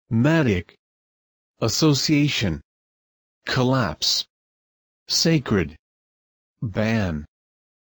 ■ヒント：単語の読み上げ音声